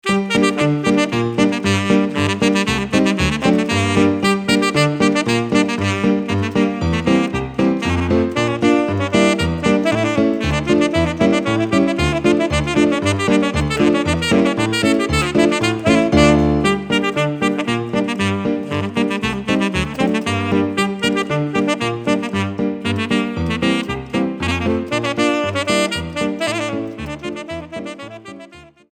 Saxophone Alto ou Tenor et Piano